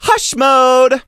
angelo_kill_vo_09.ogg